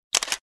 01 Shutter Sound 01.mp3